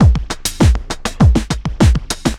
pulse tombeat 100bpm 02.wav